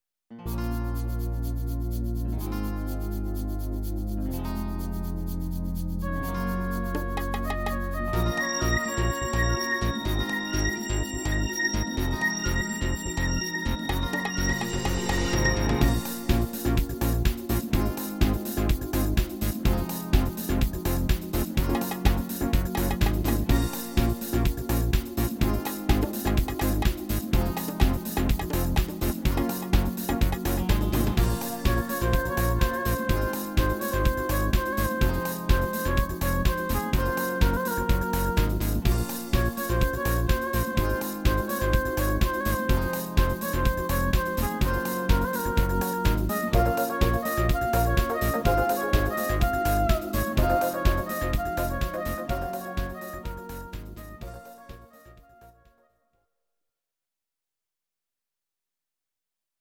Please note: no vocals and no karaoke included.